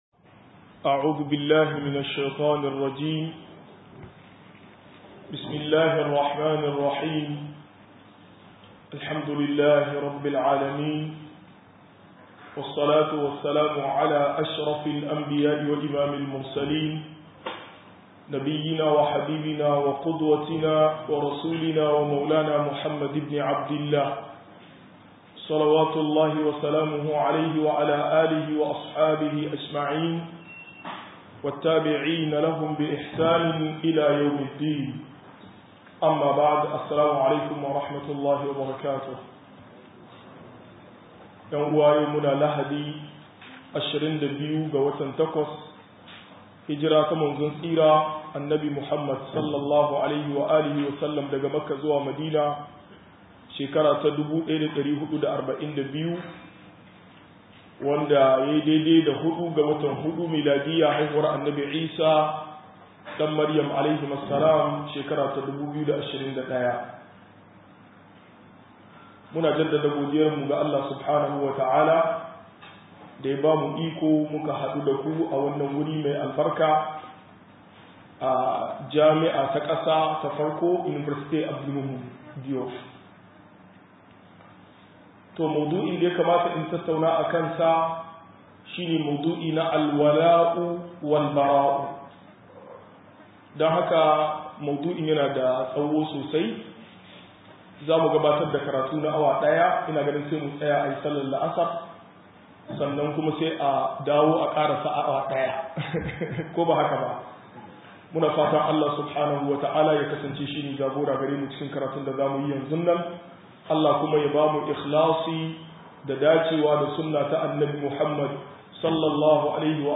120-Soyayya Da Ki Don Allah 1 - MUHADARA